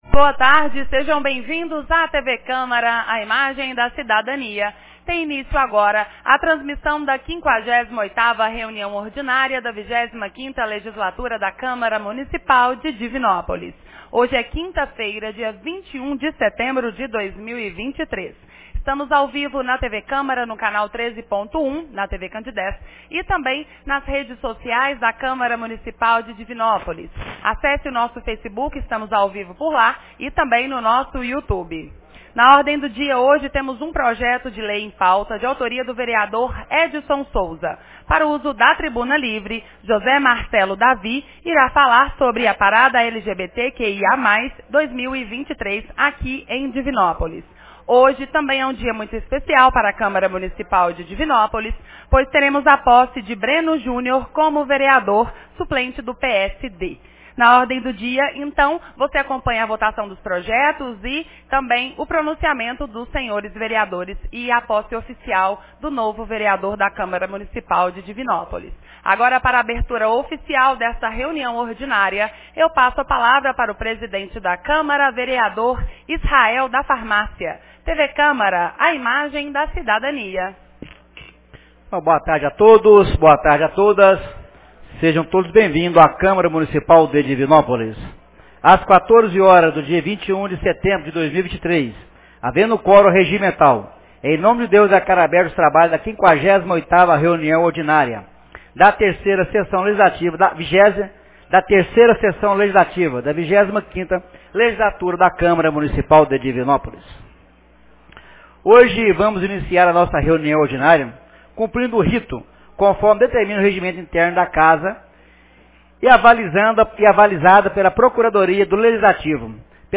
58ª Reunião Ordinária 21 de setembro de 2023